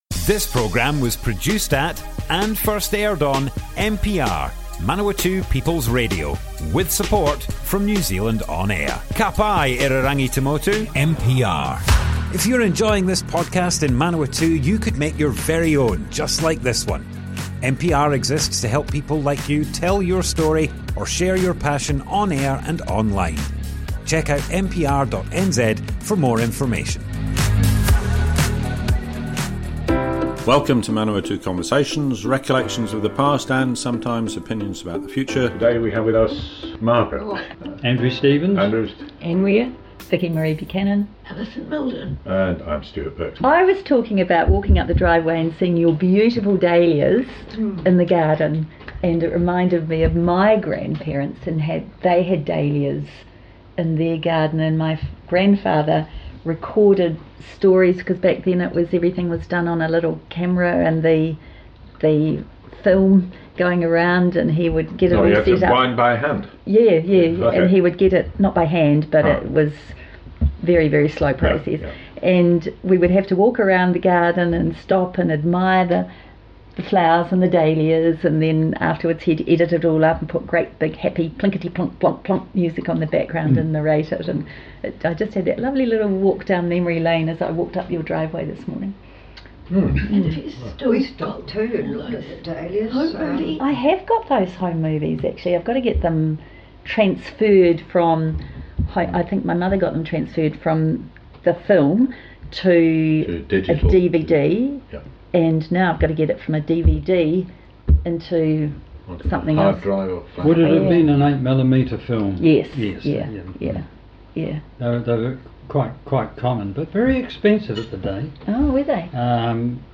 Group discussion, photography, gardens, birds